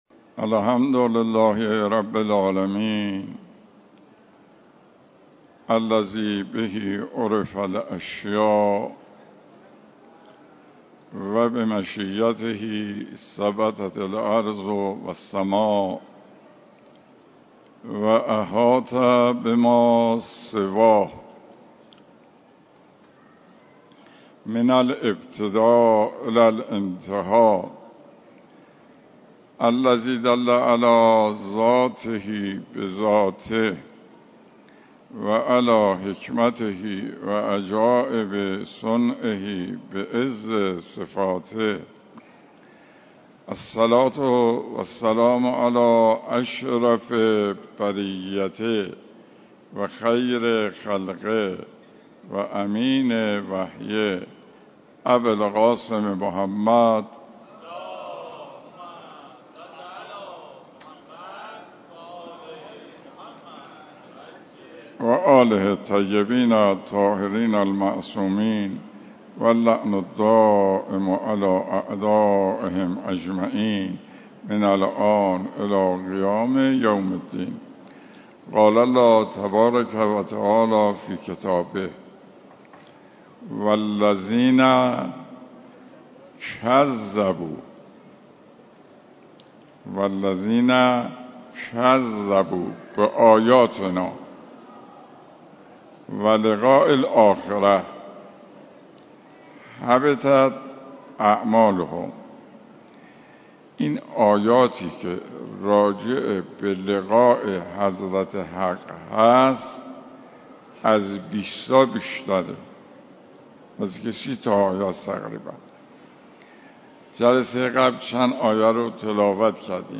جلسات درس اخلاق